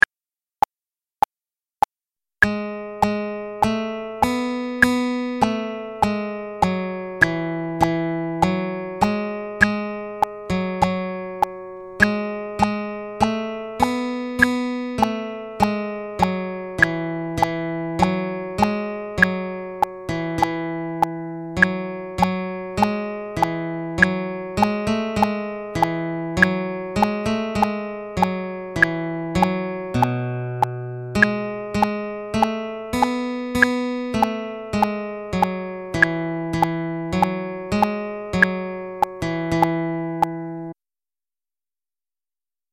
The following song is a church hymn written in the key of D.  We are going to transpose it into the key of E.